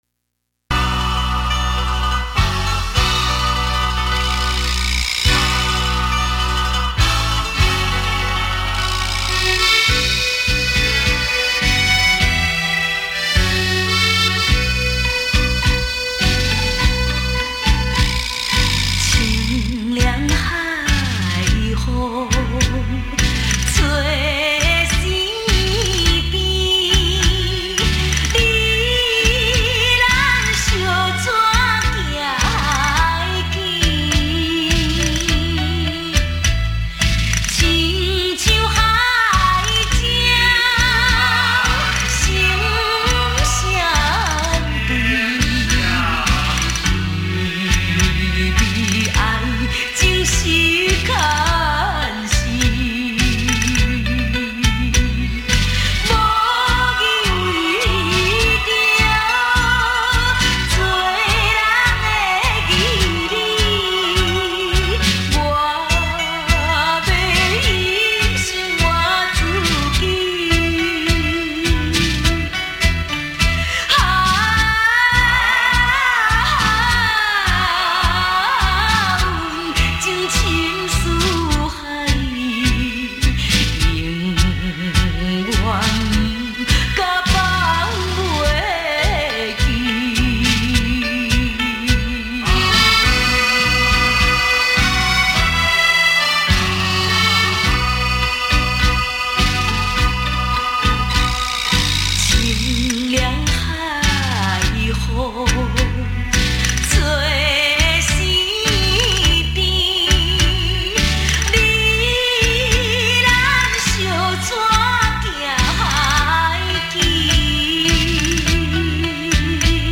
旋律简单，很容易让人朗朗上口。